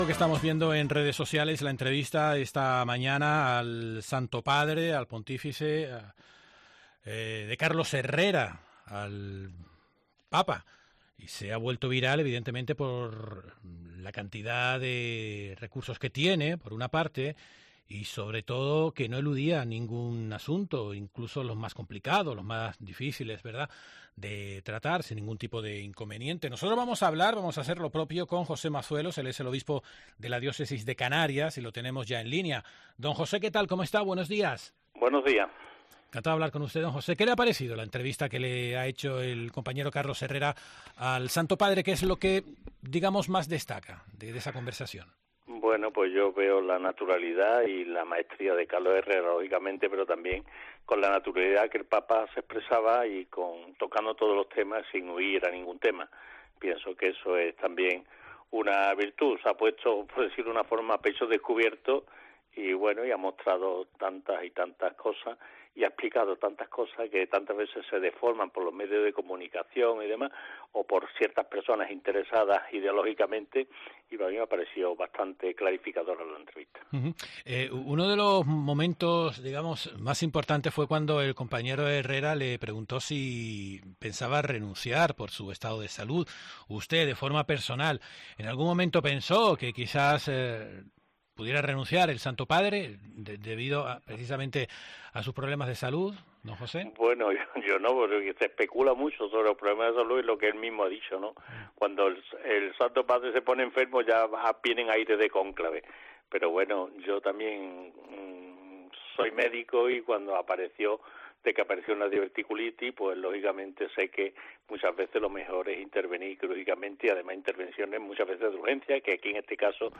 José Mazuelos, obispo de la Diócesis de Canarias